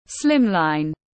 Mỏng manh tiếng anh gọi là slimline, phiên âm tiếng anh đọc là /ˈslen.dər/ .
Slimline /ˈslen.dər/